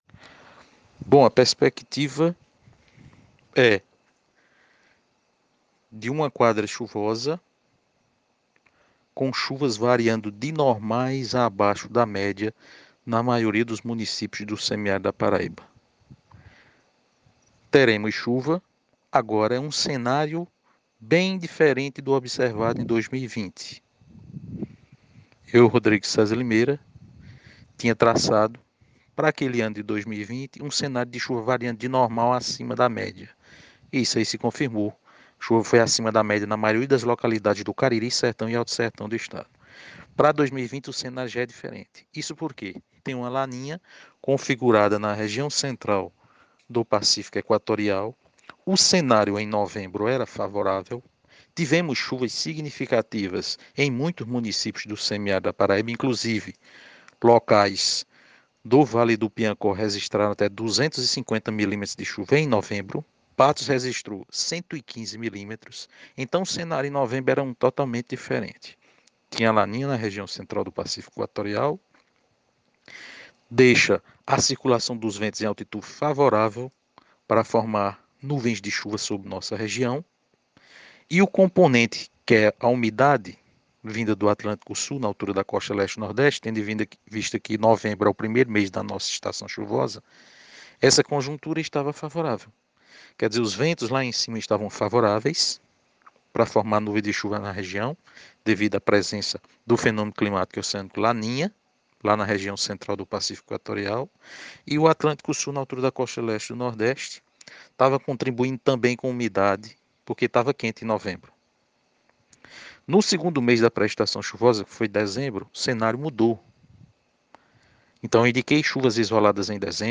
Ouça a explicação do especialista abaixo: